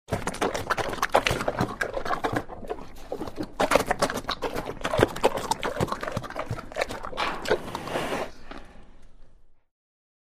Звуки животных
Медведь жует и облизывает